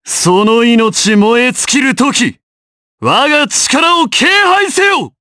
Shakmeh-Vox_Skill7_jp_b.wav